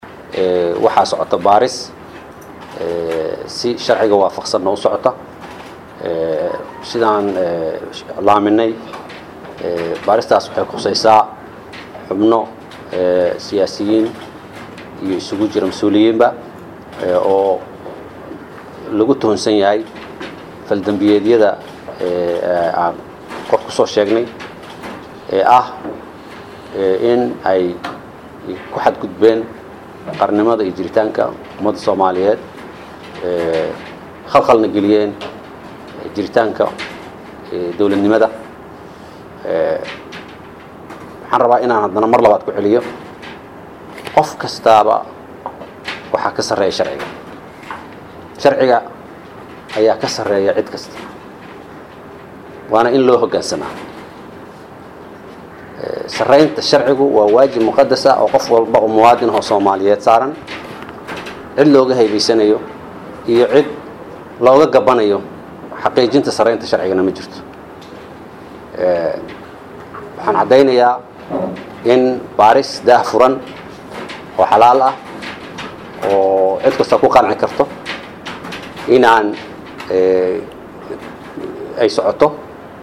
Dr Axmed Cali Daahir Xeer Ilaaliyaha Guud ee Qaranka oo maanta la hadlay Warbaahinta ayaa markale ka dhawaajiyay in dabal lagu hayo Xildhibaano ka tirsan Baarlamaanka iyo Siyaasiyiin uu sheegay in ay ku howlan yihiin arrimo ka dhan ah jiritaanka Ummadda Soomaaliyeed.